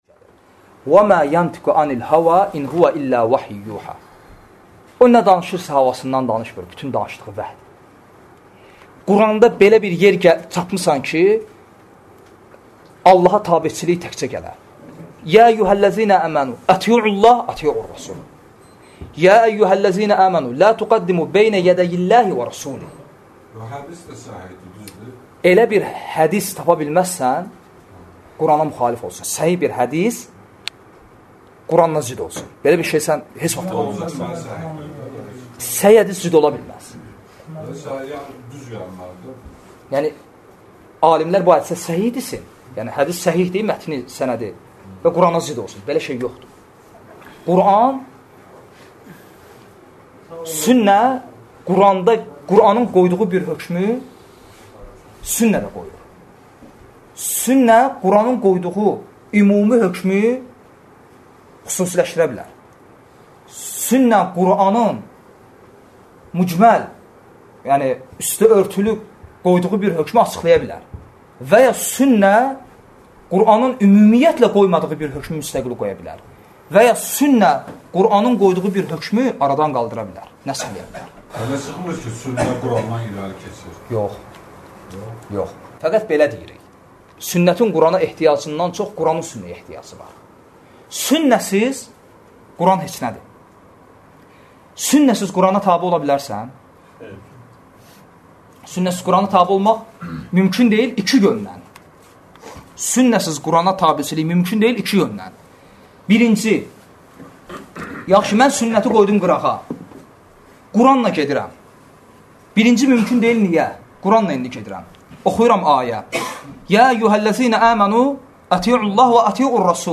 Dərslərdən alıntılar – 86 parça